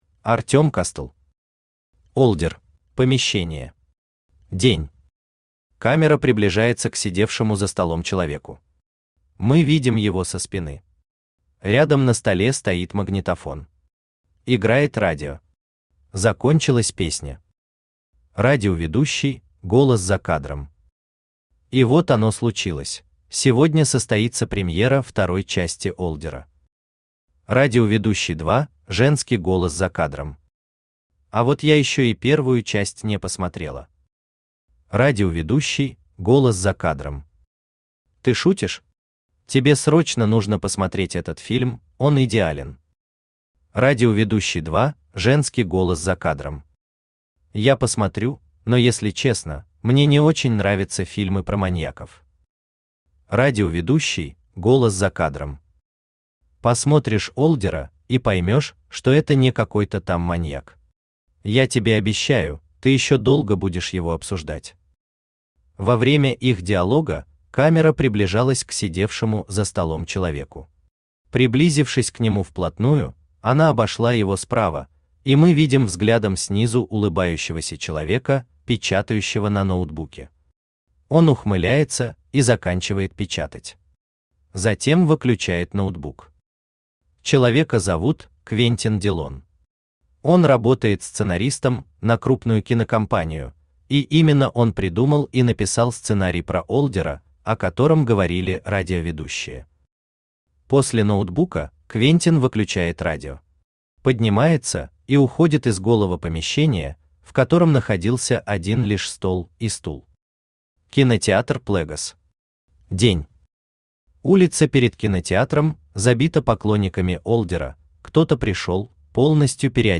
Aудиокнига Олдер Автор Артем Кастл.